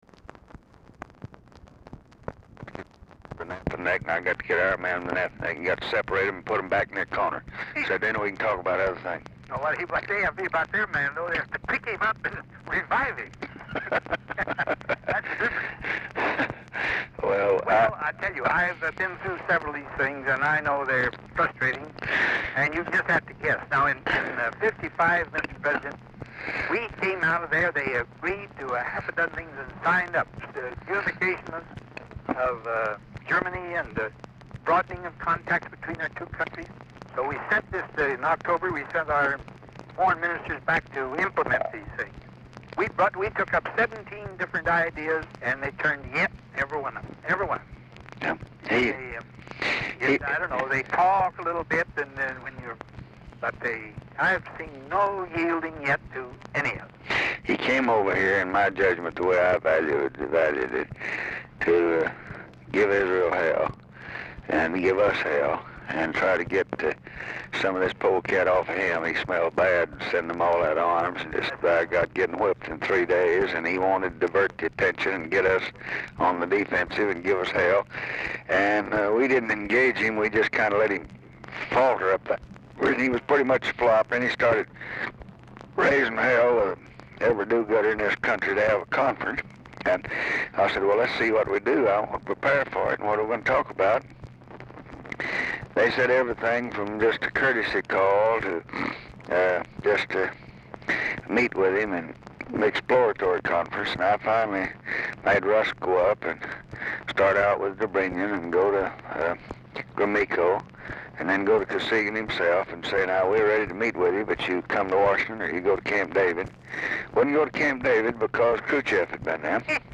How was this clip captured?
SKIPPING IN RECORDING Format Dictation belt Location Of Speaker 1 Mansion, White House, Washington, DC